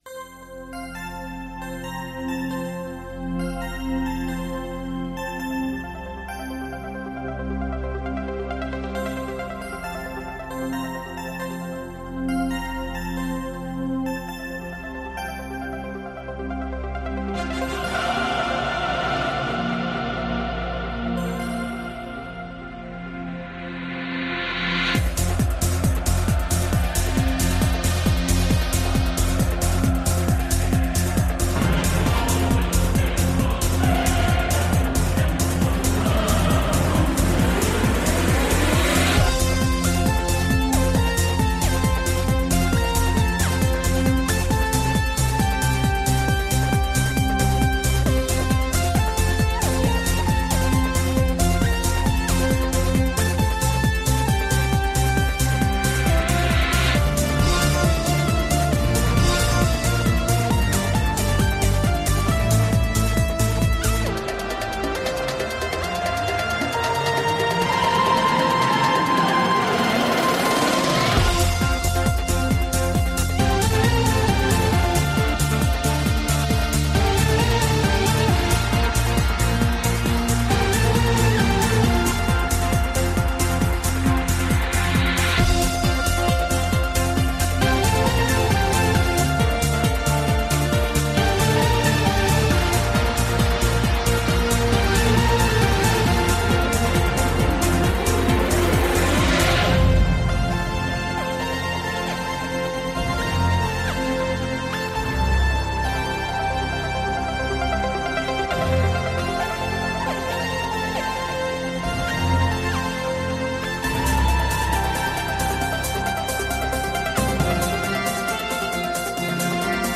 Elkarrizketa